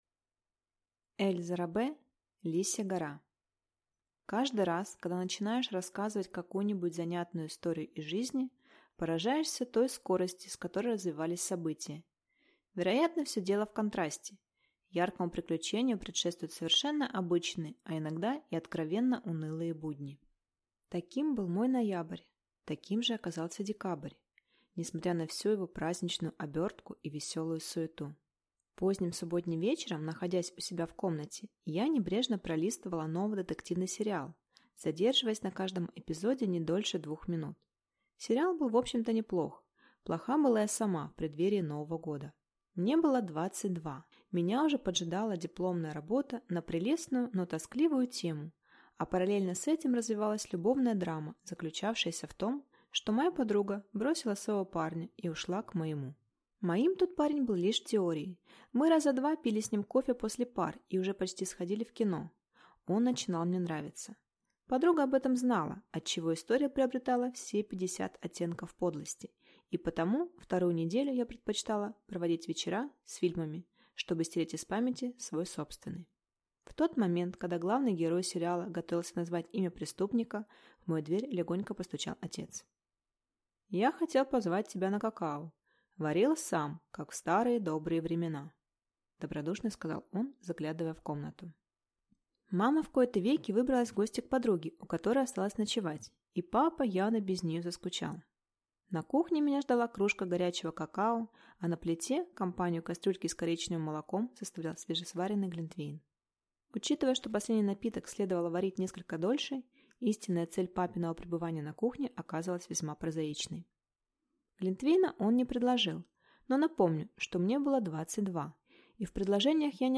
Аудиокнига Лисья Гора | Библиотека аудиокниг